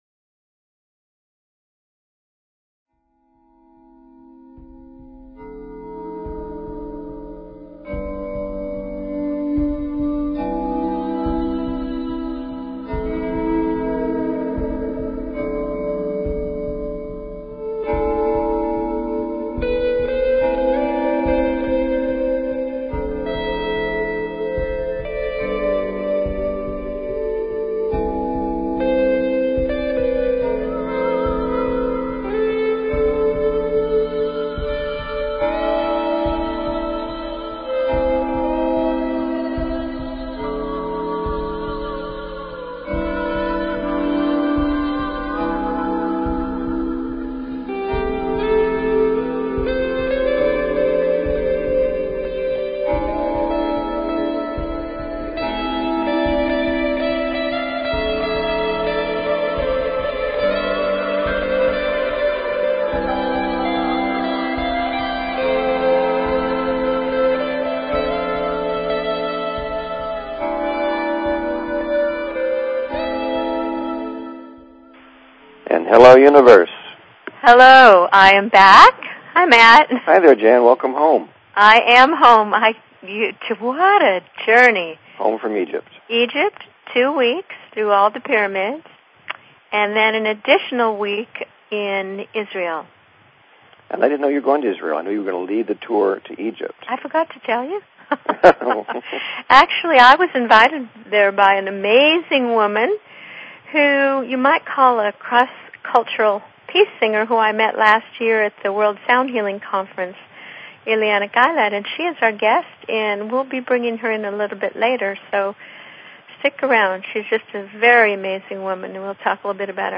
Talk Show Episode, Audio Podcast, Live_from_Ordinary_Miracles and Courtesy of BBS Radio on , show guests , about , categorized as
Join us and our guests, leaders in alternative Health Modalities and Sound Healing, every Wednesday evening at the Ordinary Miracles Store in Cotati,California. We love call in questions!!!